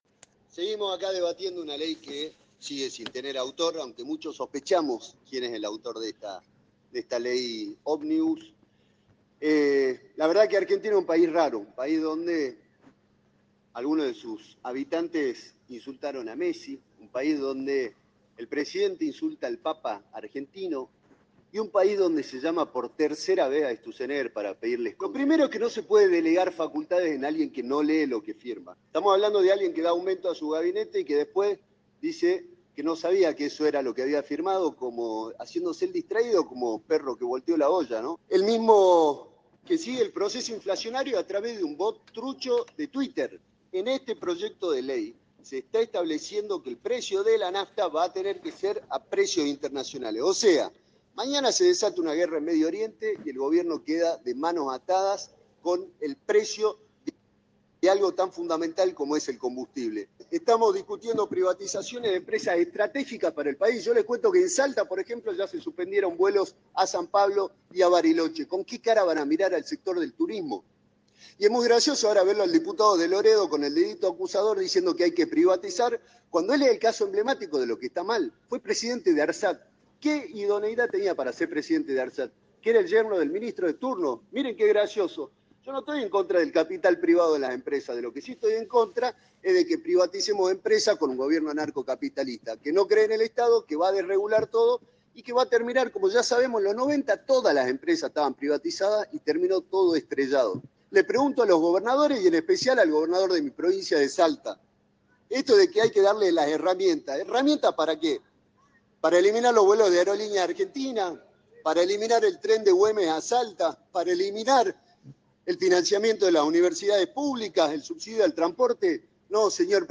El diputado Nacional por Salta habló durante la Sesión de Diputados, comentó acerca de Milei y su accionar como presidente, además de que criticó fuertemente las medidas que quiere impulsar la Ley Bases en todo el país.